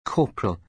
ttsmp3_RaS3uPQ.mp3